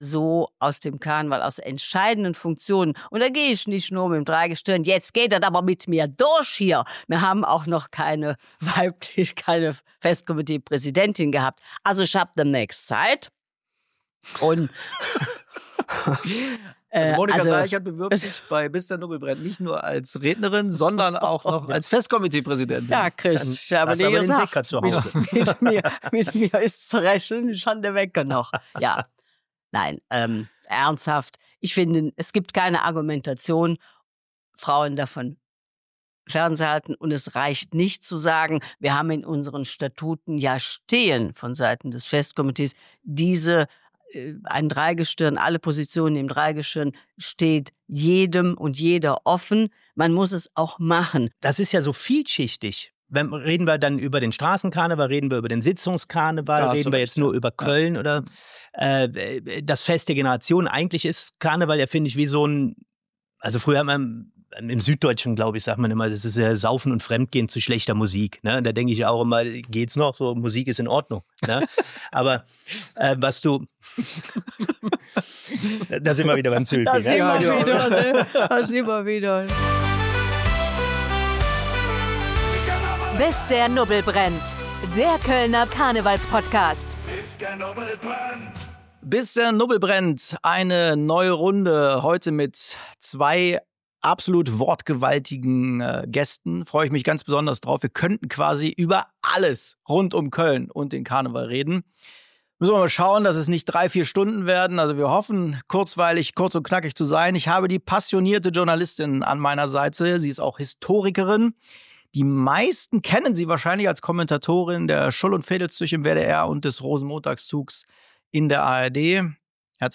Ein unterhaltsames Gespräch voller Anekdoten, Einordnungen und persönlicher Perspektiven.